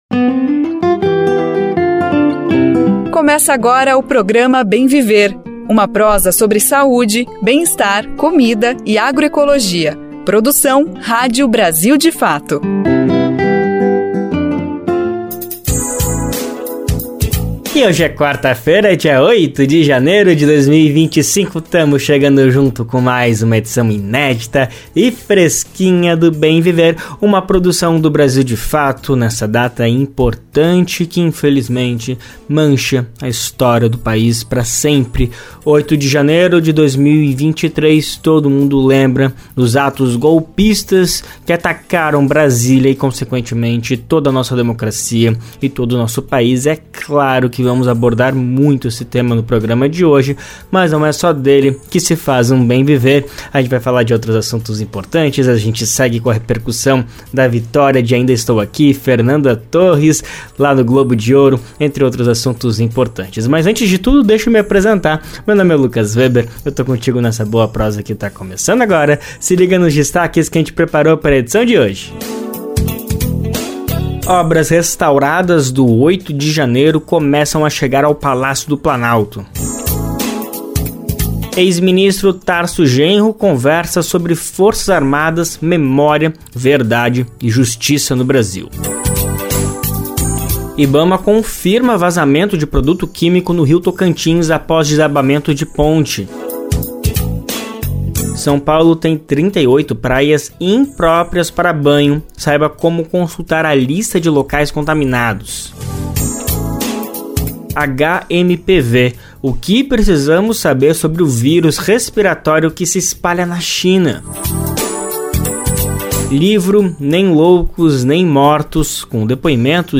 A conversa foi veiculada no programa Bem Viver desta quarta-feira (8).